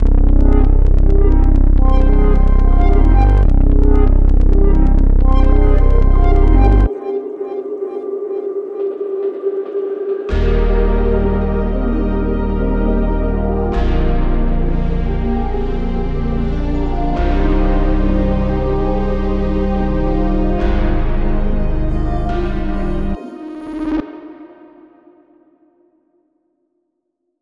300+ One Shots bursting with distorted textures, glitchy modulation & gritty noise.